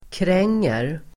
Uttal: [kr'eng:er]